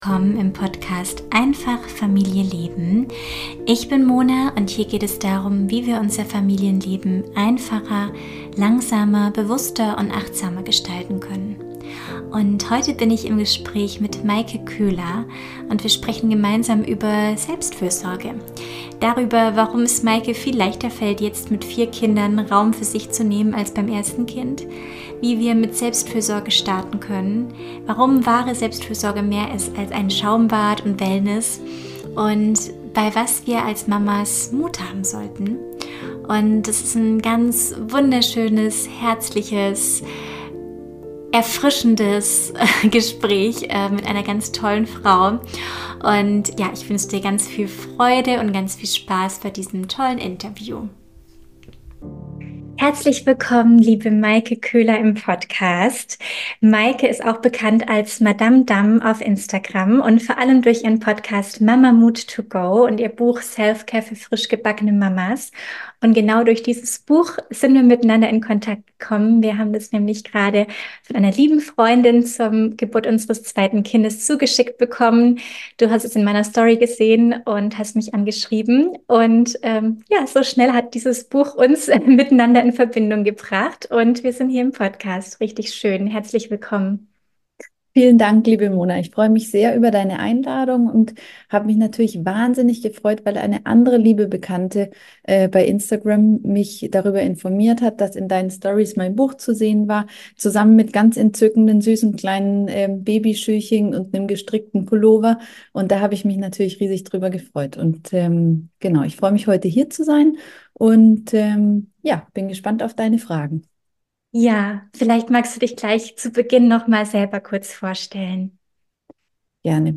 52 - Selbstfürsorge und Mama-Mut - Interview